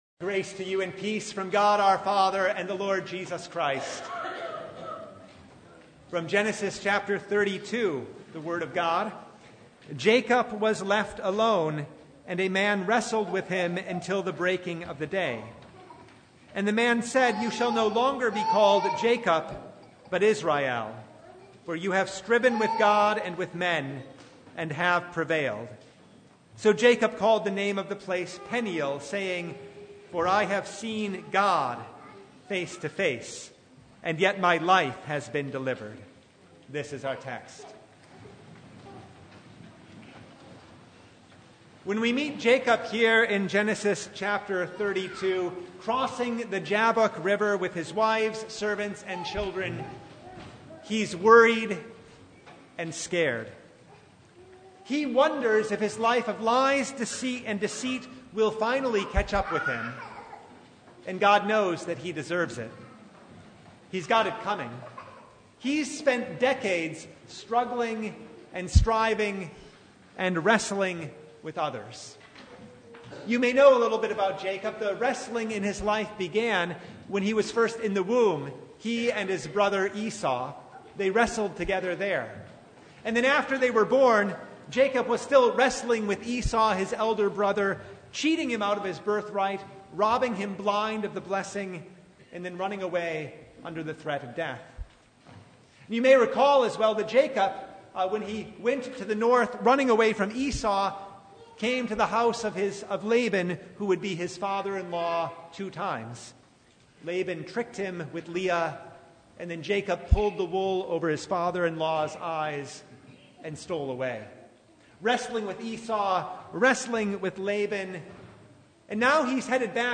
Genesis 32:22-31 Service Type: Sunday Bible Text